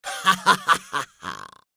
the-sound-of-laughing-witch